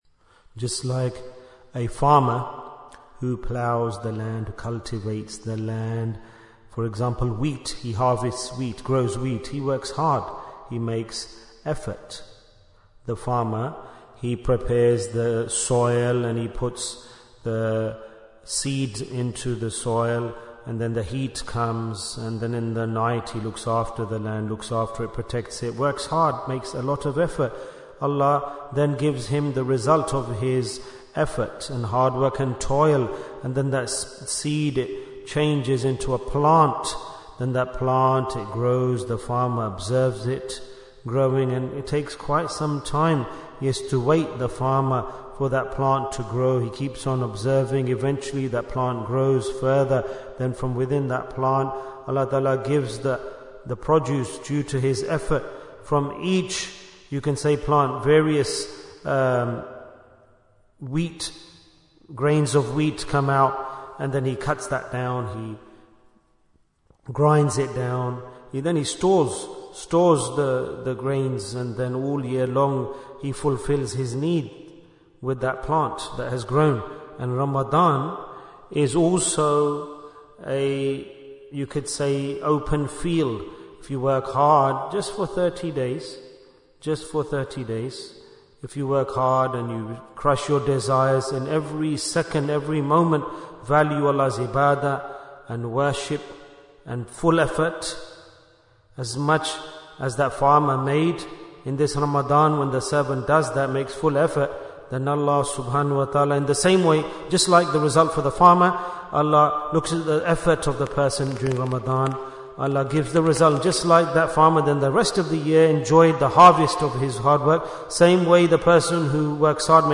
Jewels of Ramadhan 2025 - Episode 4 Bayan, 20 minutes4th March, 2025